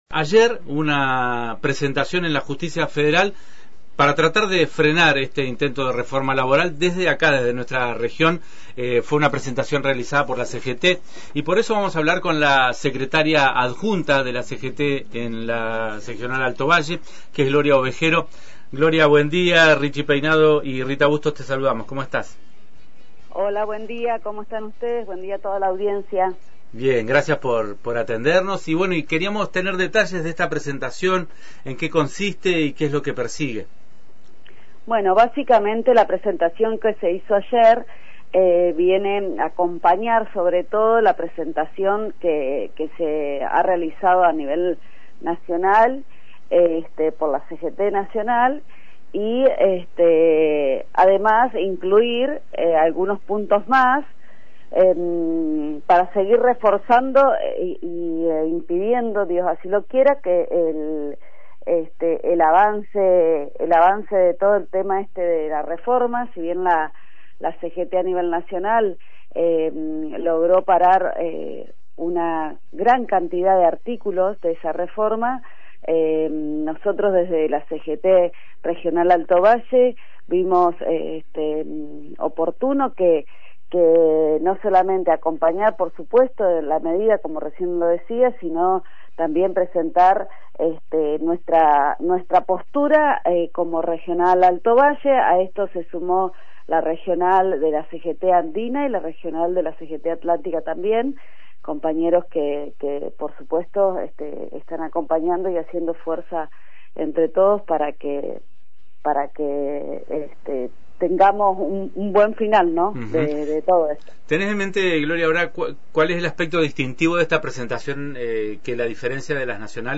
En diálogo con Antena Libre